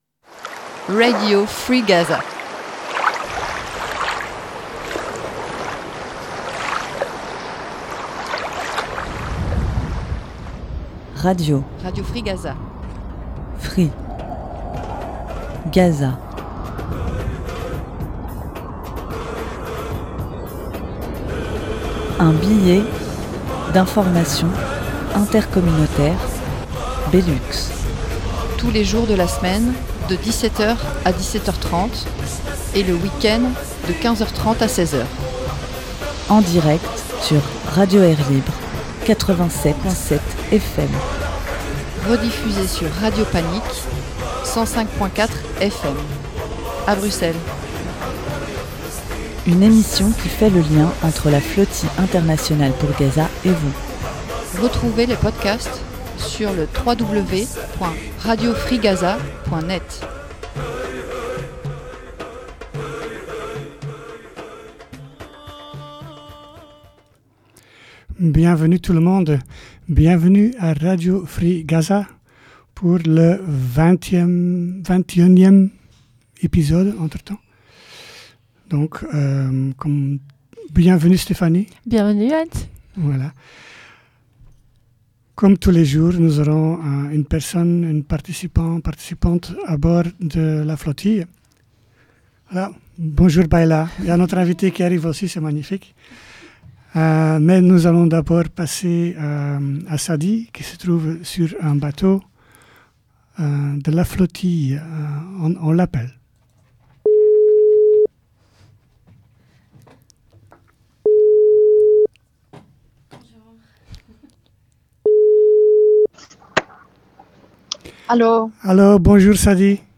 Comme tous les jours, un direct avec un.e participant.e de la délégation belge et luxembourgeoise de la Flotille Mondiale du Sumud.